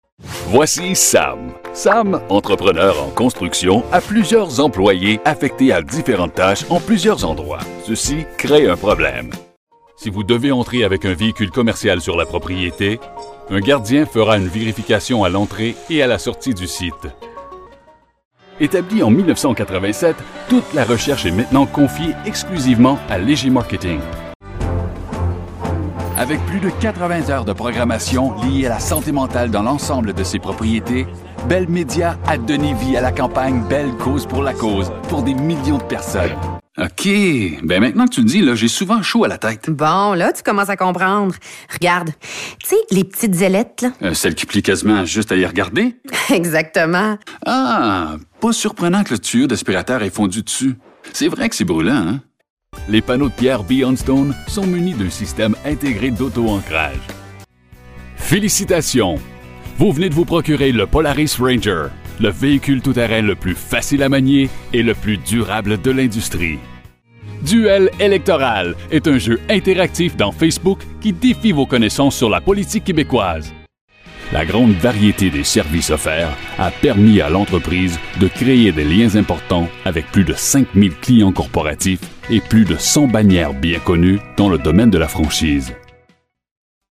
Bilingual, English and French Canadian Male voice over (voice off) and narrator for adversting, documentaries and corporate videos
Sprechprobe: Industrie (Muttersprache):